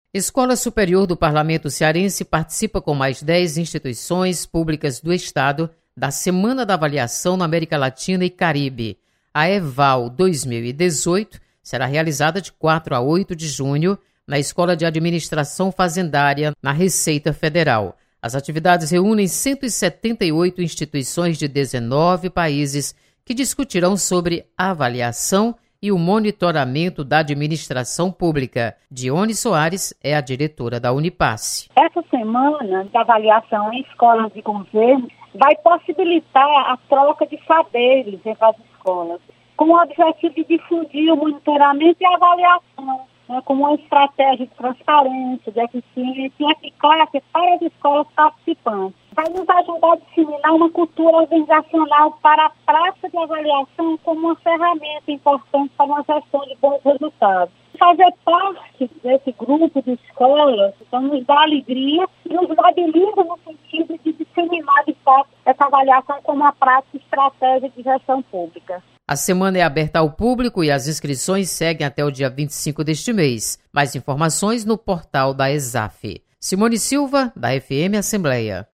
Escola Superior do Parlamento Cearense participa de evento internacional de avaliação da Administração pública. Repórter